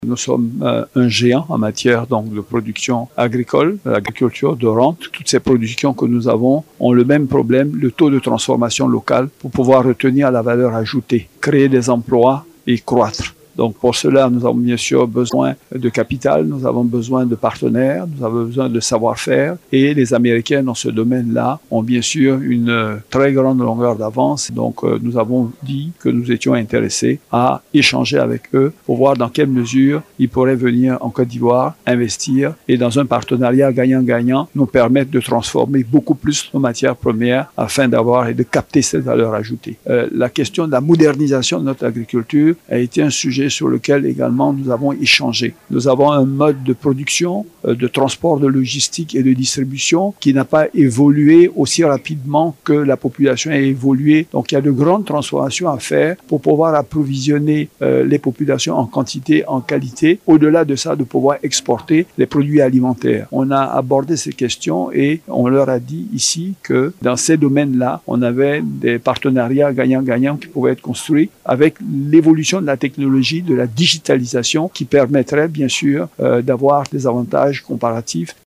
A Washington où il a pris part le mercredi 14 décembre 2022 à la cérémonie d’ouverture officielle de l’US Africa Leaders Summit, le Premier Ministre ivoirien, Patrick Achi, a exprimé, au terme de la rencontre, la volonté de la Côte d’Ivoire de nouer un partenariat gagnant-gagnant avec les États-Unis.
On écoute Patrick ACHI